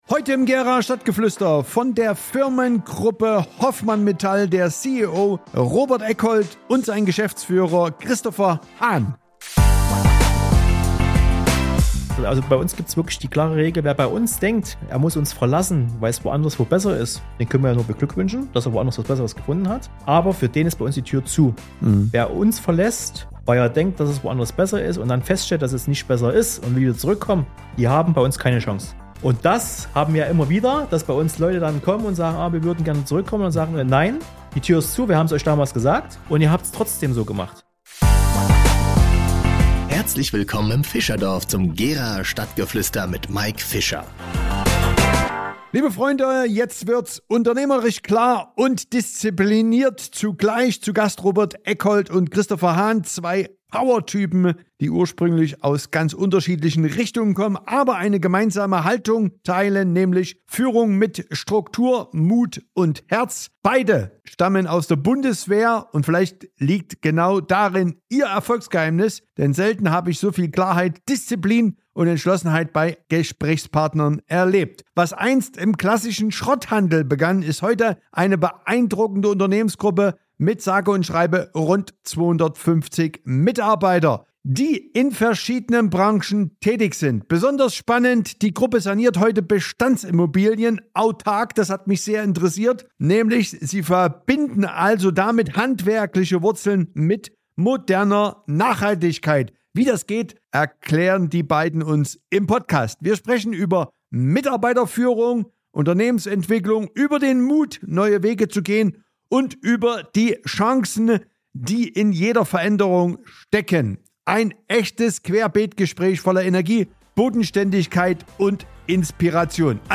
Wir sprechen über Mitarbeiterführung, Unternehmensentwicklung, den Mut, neue Wege zu gehen, und über die Chancen, die in jeder Veränderung stecken. Ein echtes Querbeet-Gespräch voller Energie, Bodenständigkeit und Inspiration – mit zwei Unternehmern, die zeigen, dass Erfolg kein Zufall ist, sondern das Ergebnis von Haltung und Tatkraft.